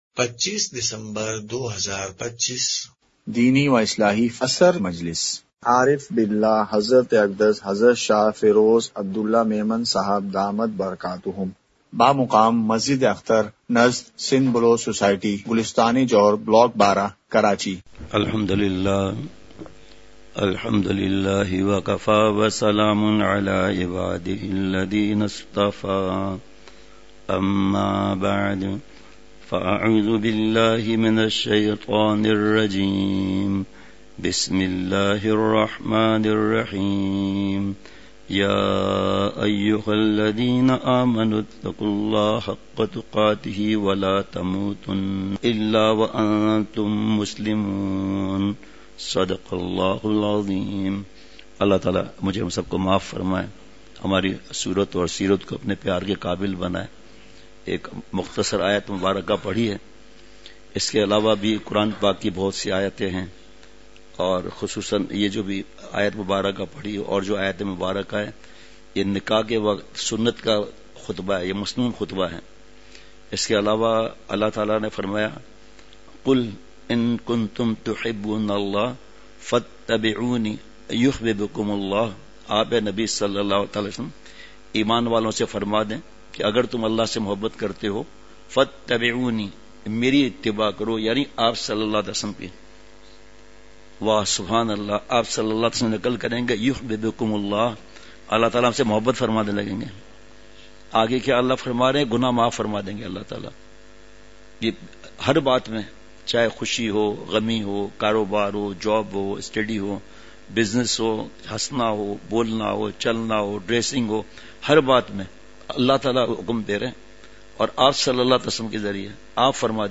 اصلاحی مجلس
مقام:مسجد اختر نزد سندھ بلوچ سوسائٹی گلستانِ جوہر کراچی